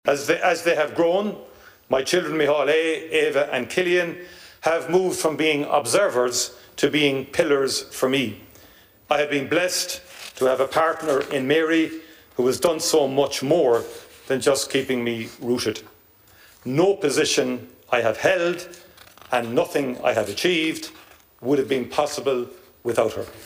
Speaking in the Dail following his election, he paid tribute to his family - who were in the visitors gallery.